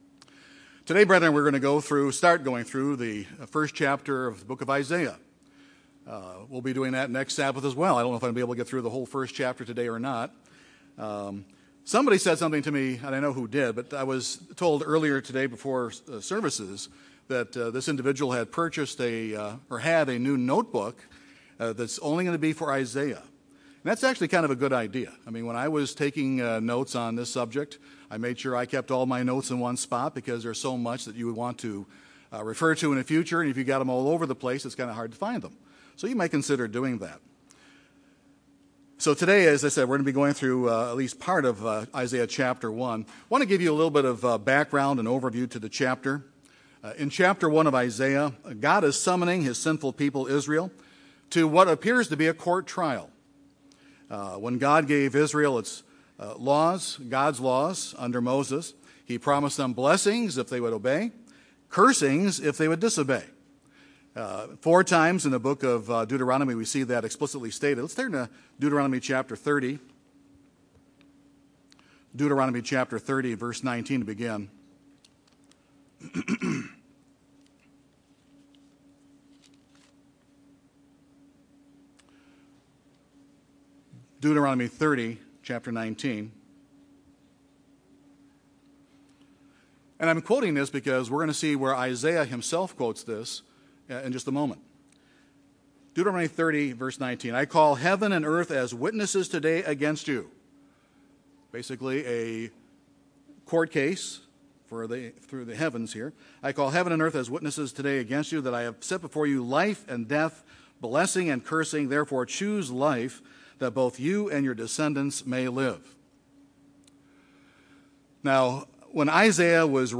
Isaiah Bible Study Series - Chapter 1